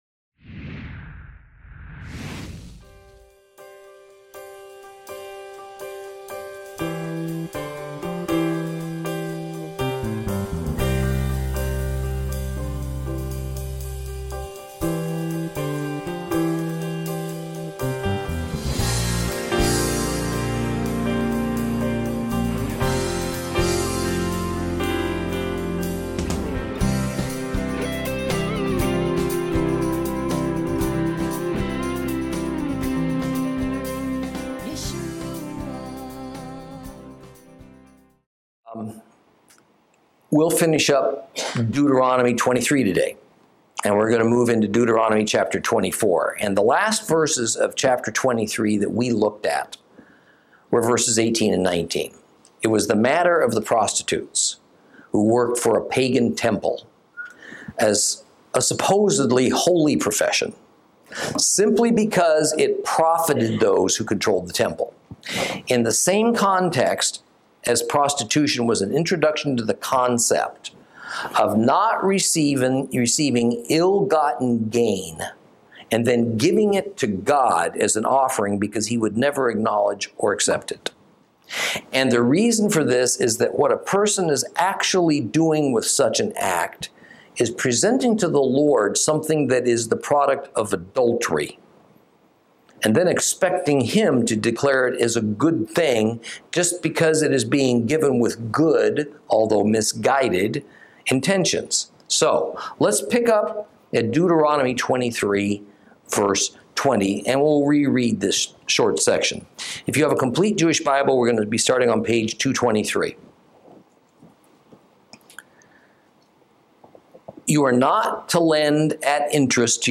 Lesson 32 Ch23 Ch24 - Torah Class